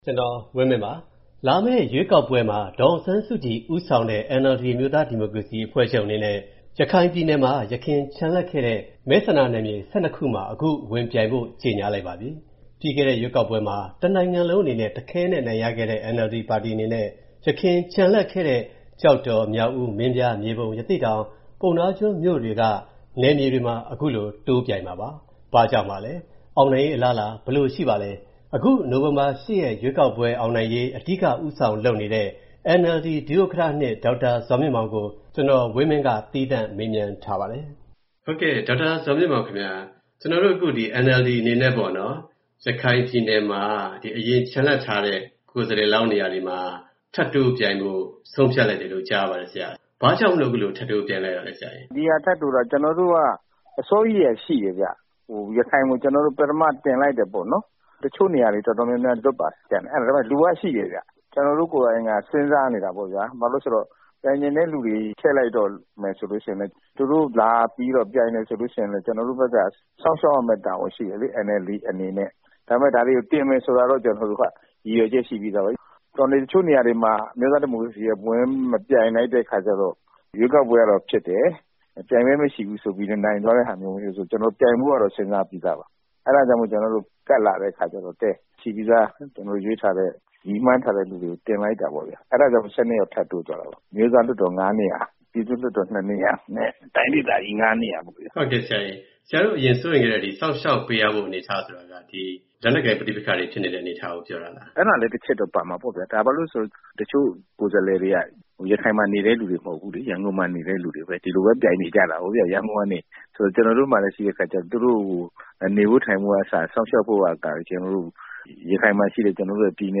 သီးသန့ျမေးမွနျးထားပါတယျ။